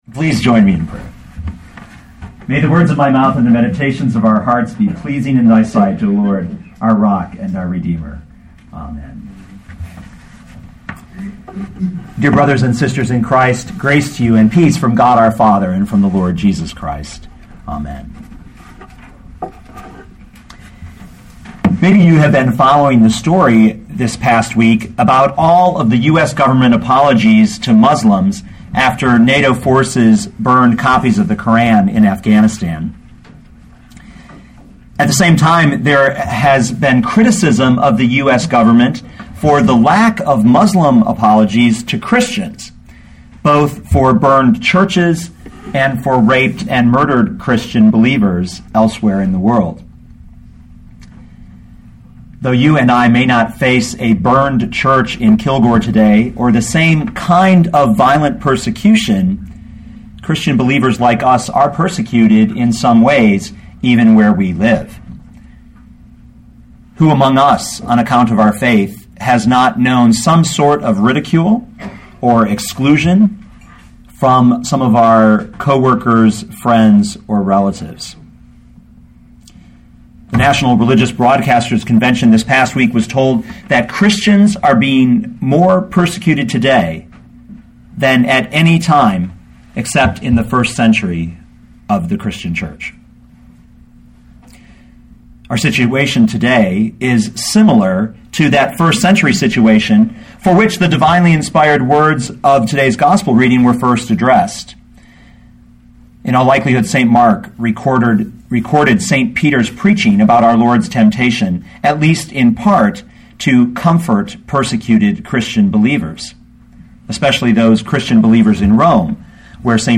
2012 Mark 1:12-13 Listen to the sermon with the player below, or, download the audio.